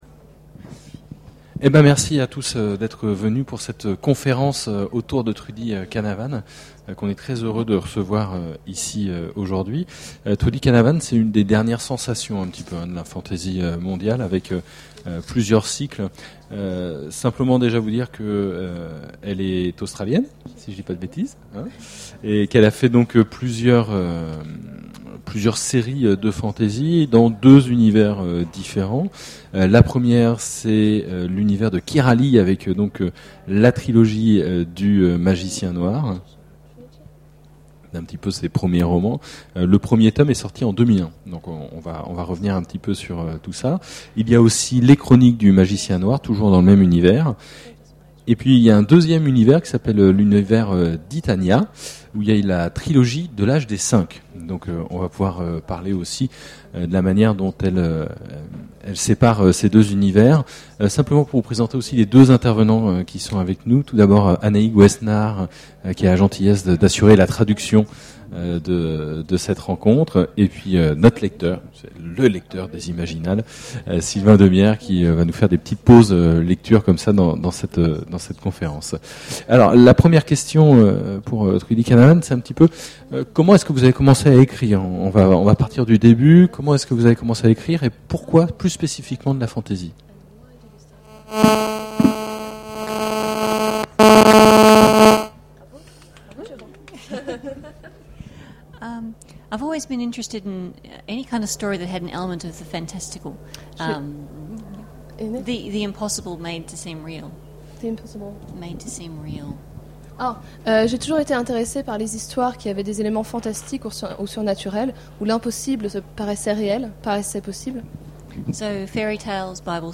Imaginales 2011 : Conférence rencontre avec Trudi Canavan
Voici l'enregistrement de la rencontre avec Trudi Canavan...
Imaginales 2011 : Conférence rencontre avec Trudi Canavan Voici l'enregistrement de la rencontre avec Trudi Canavan...
Mots-clés Rencontre avec un auteur Conférence Partager cet article